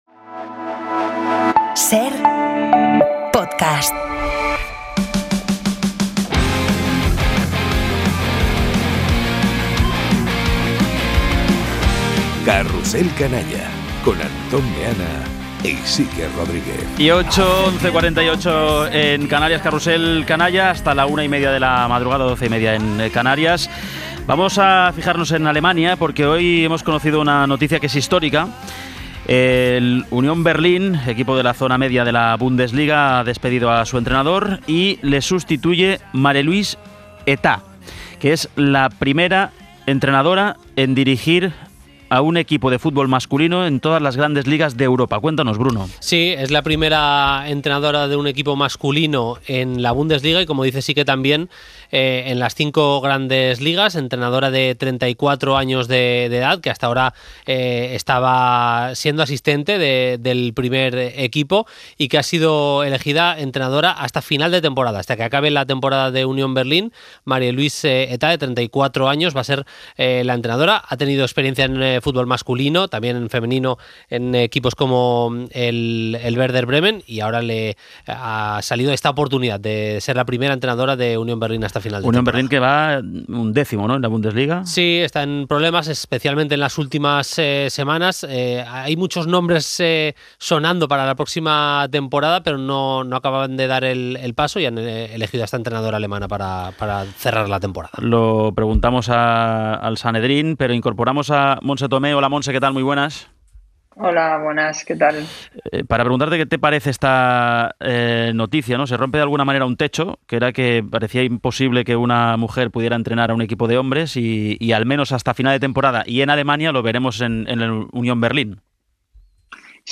Entrevista a Montse Tomé y repaso a la jornada polideportiva: Alcaraz, Paris-Roubaix y Masters de Augusta